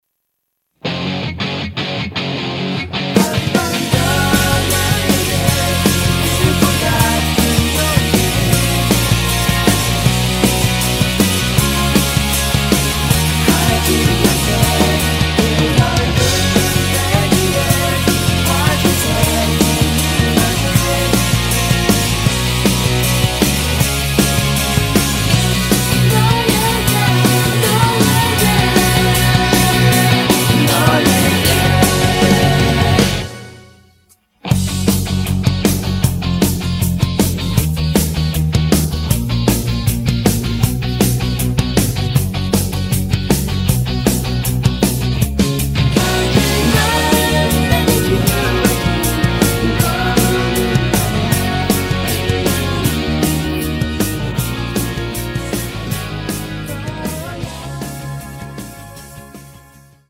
음정 원키 3:13
장르 가요 구분 Voice MR